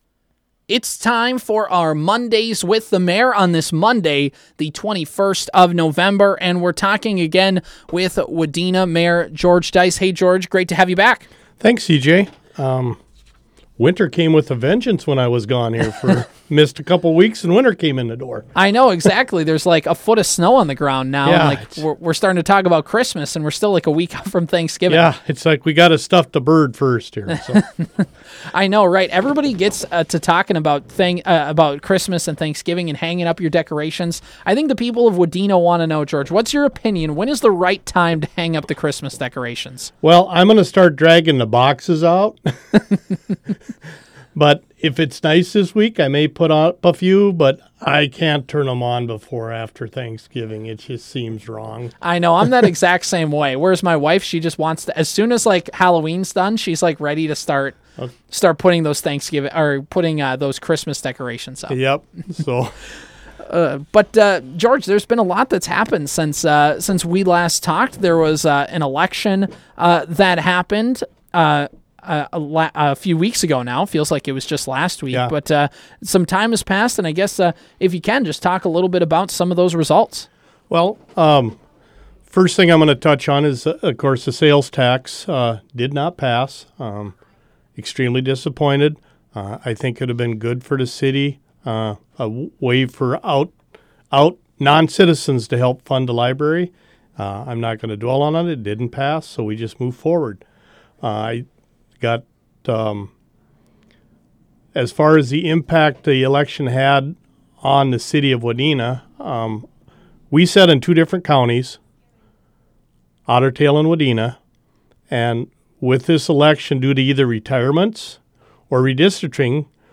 Wadena Mayor George Deiss stopped in studio to give an update on the City of Wadena and some other city announcements.
You can hear our conversation with Mayor Deiss below!